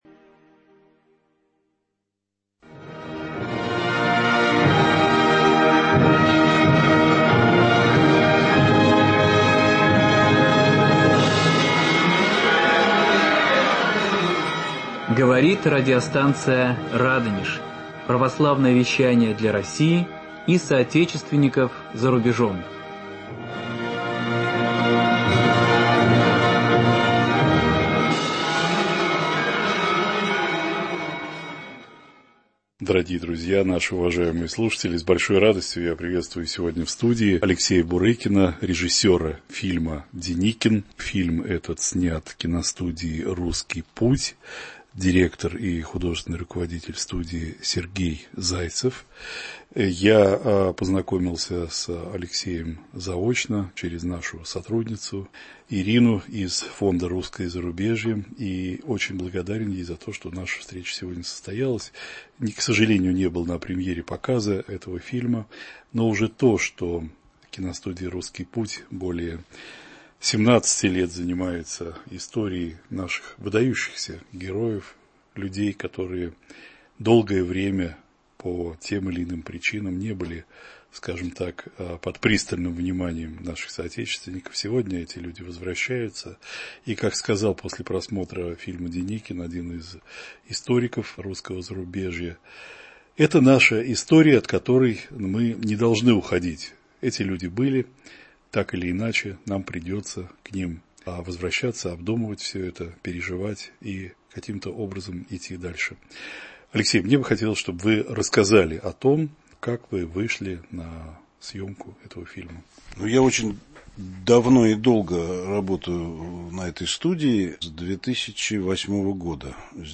Режиссер обязан в полноте понять эту личность, о которой он делает фильм, и показать самое главное. В студии